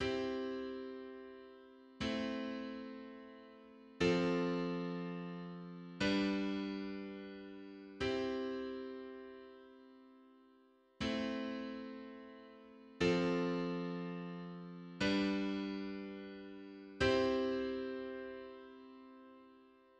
Ein typisk akkordprogresjon av doo-wop i C-dur (
50s_progression_in_C.mid.mp3